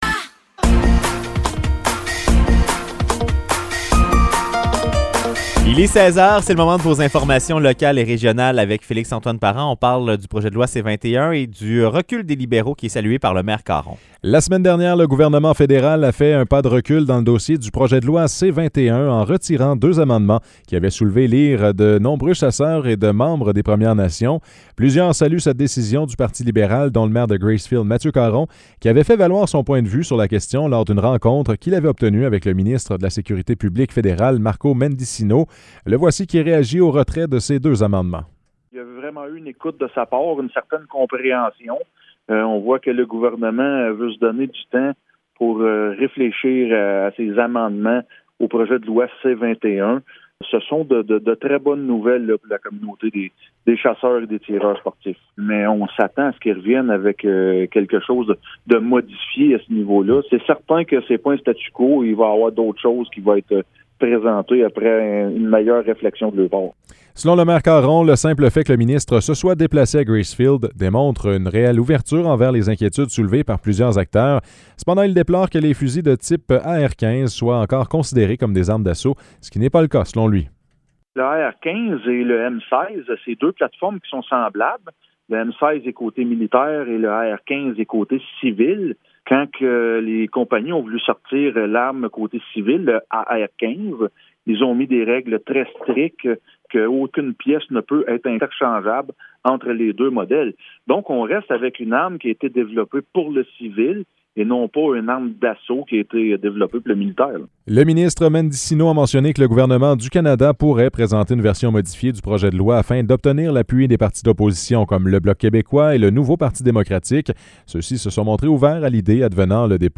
Nouvelles locales - 7 février 2023 - 16 h